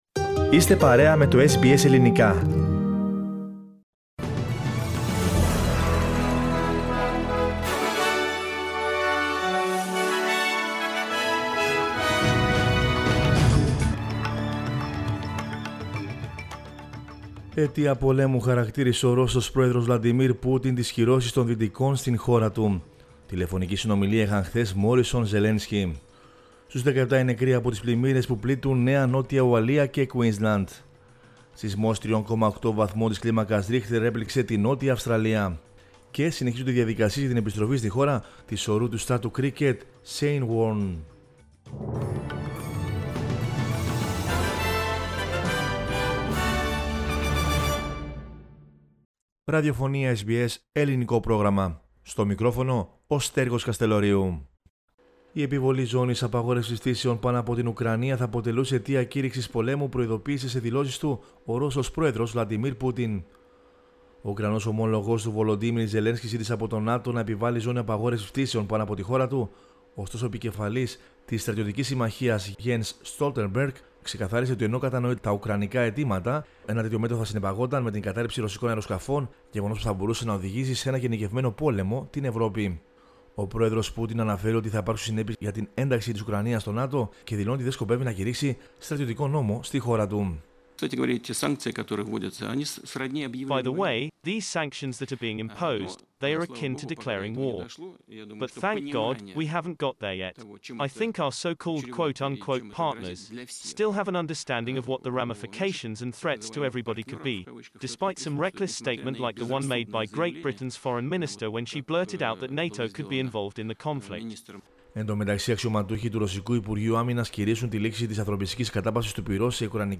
News in Greek from Australia, Greece, Cyprus and the world is the news bulletin of Sunday 6 March 2022.